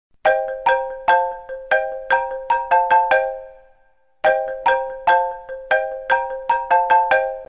Download Xylophone sound effect for free.
Xylophone